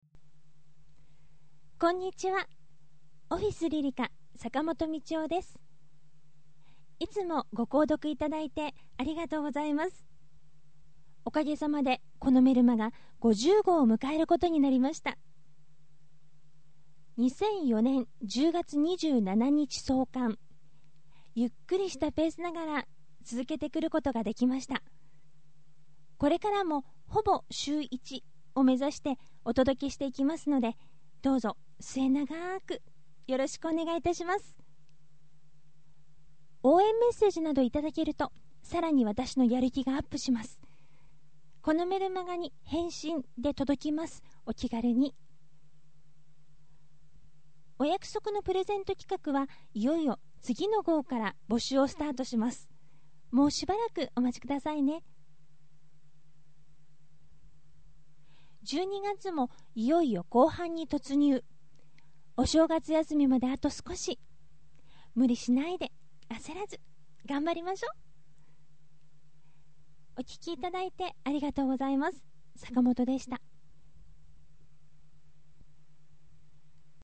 初のボイスメッセージです。